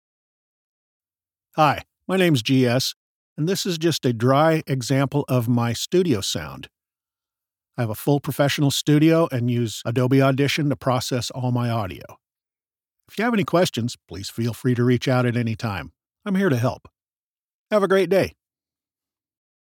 Male
Studio Quality Sample
Dry Sample Of My Booth
Words that describe my voice are Storyteller, Conversational, Relatable.